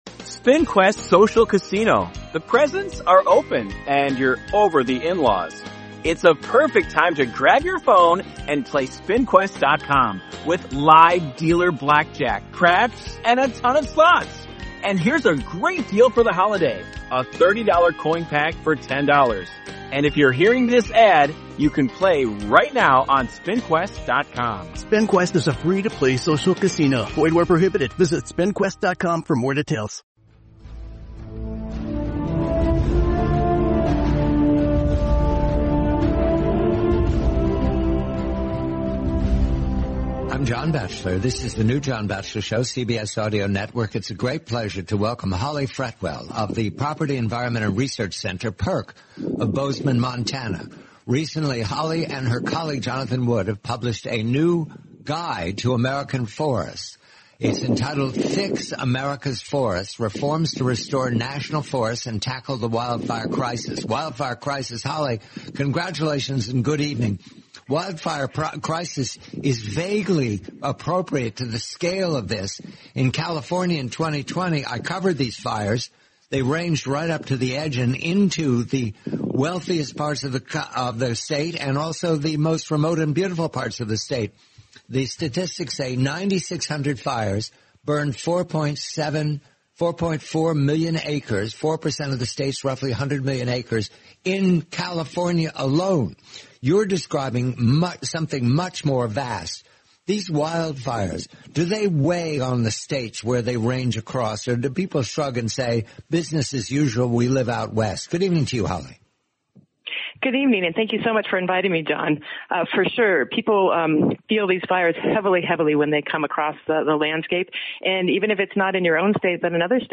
The complete, forty-minute interview.